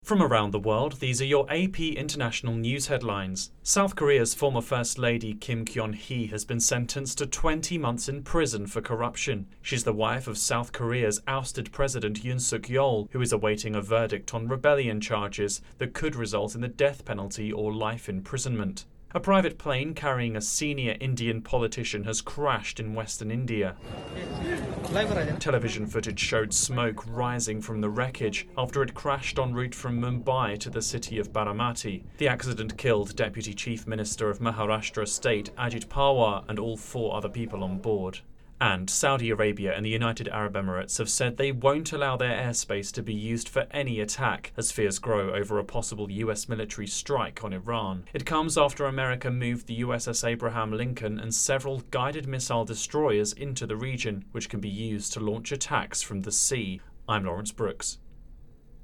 AP International News Headlines